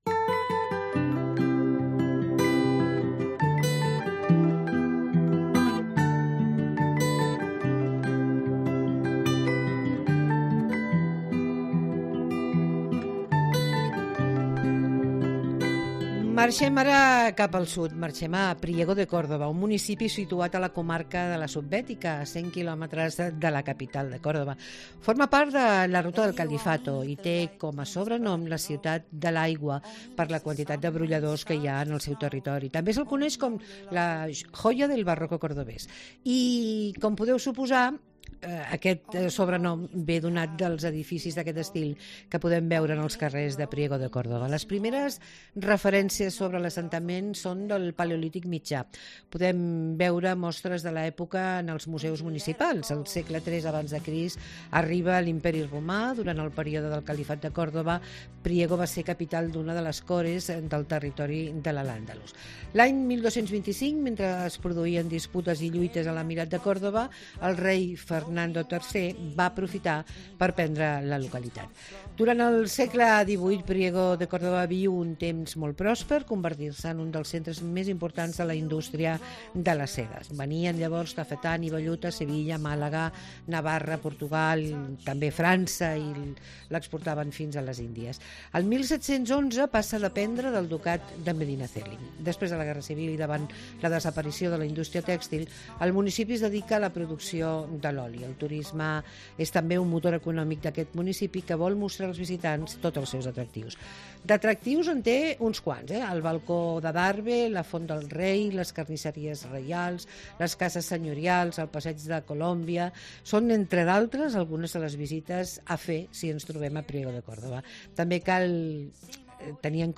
Todos los fines de semana hacemos tres horas de radio pensadas para aquellos que les gusta pasarlo bien en su tiempo de ocio ¿donde?